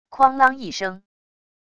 哐啷一声wav音频